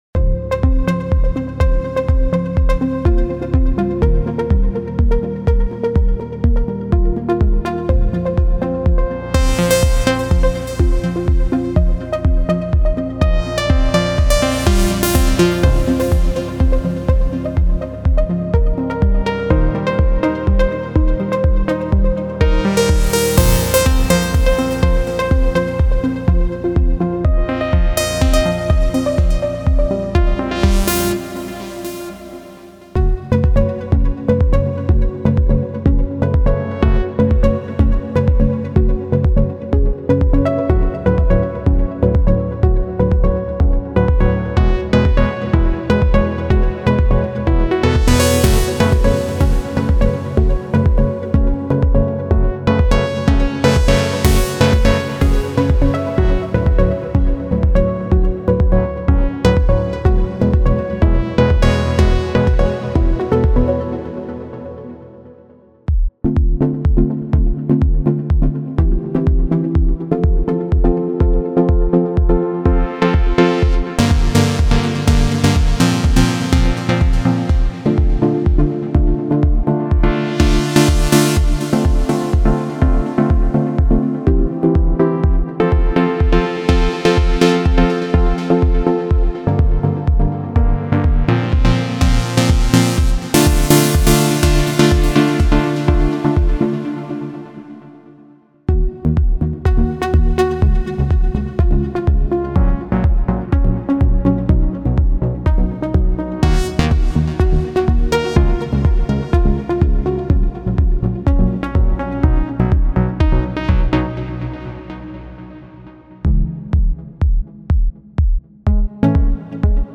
这款专业的 MIDI 音色包包含丰富的旋律、主题和拨弦音色，旨在激发您的创作灵感，助您瞬间突破创作瓶颈。
探索能够激发完整曲目的旋律主题和拨弦音色，以及不断演进的和弦进行和节奏细节，让您的灵感源源不断。
• 力度感应编程，实现即时、富有表现力的演奏